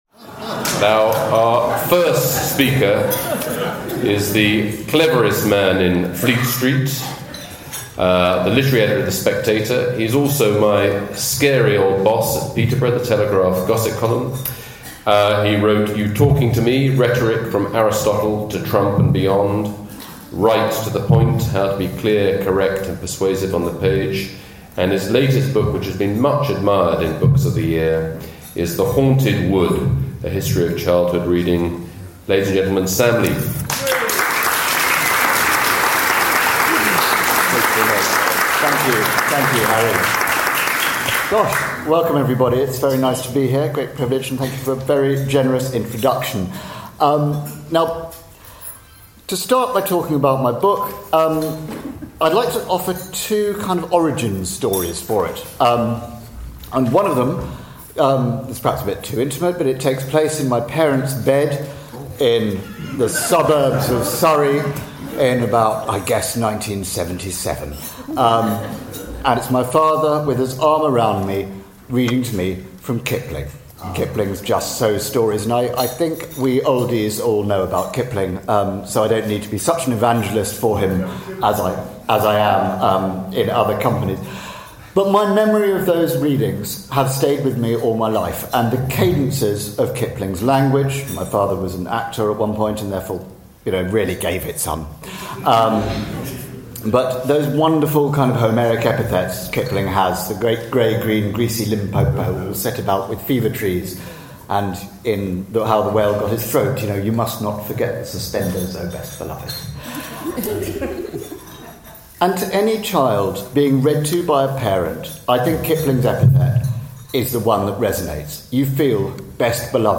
Sam was speaking at The Oldie Literary Lunch on 10th December 2024.